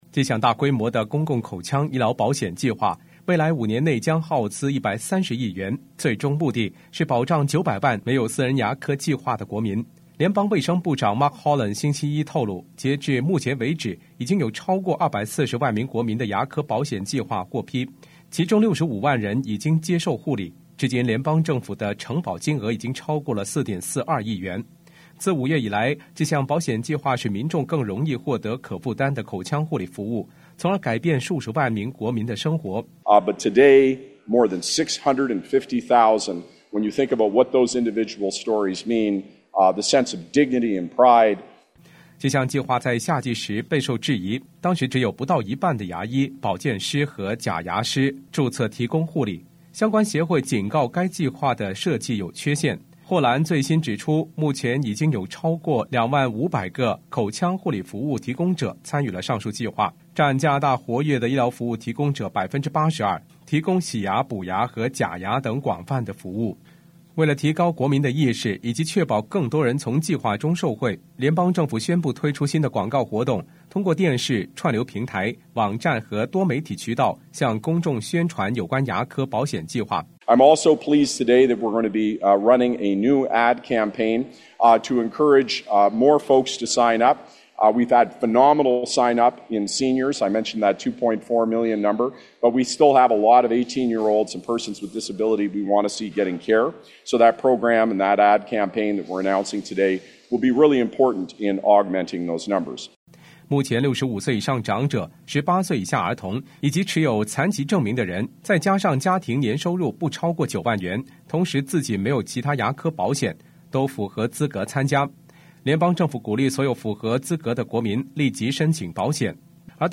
報道